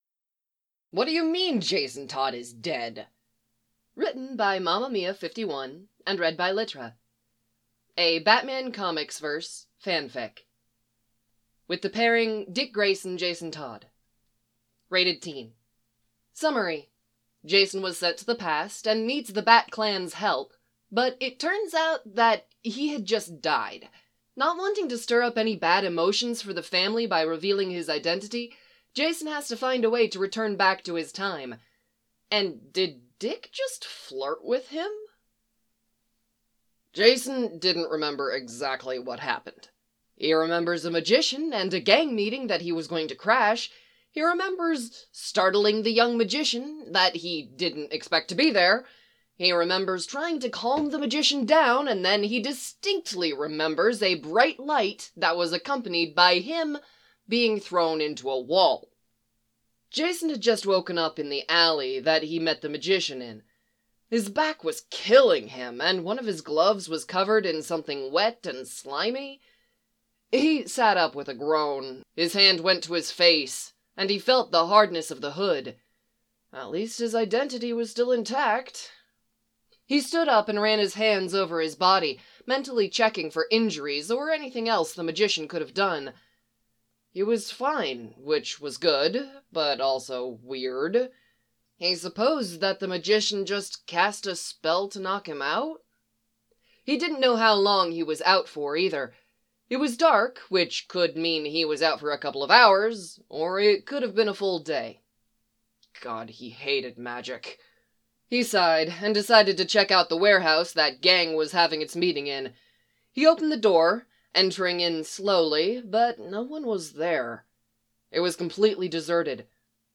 [podfic]